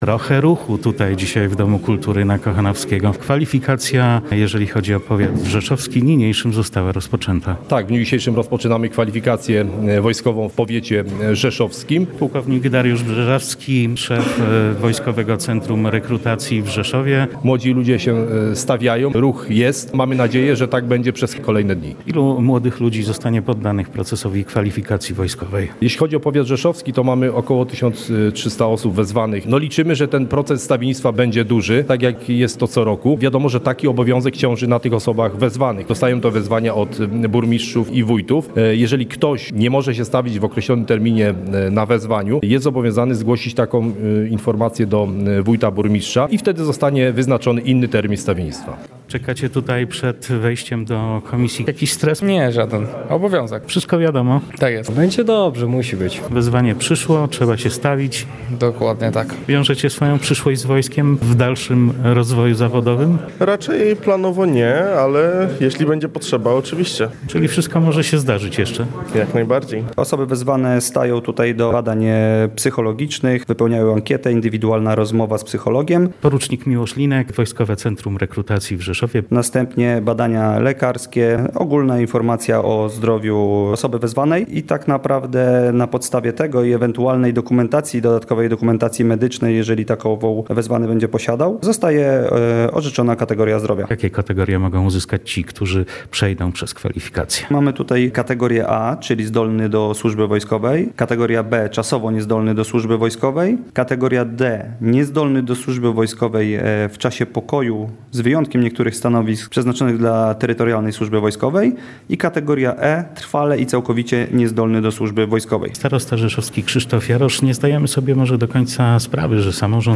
W powiecie rzeszowskim rozpoczęła się kwalifikacja wojskowa • Relacje reporterskie • Polskie Radio Rzeszów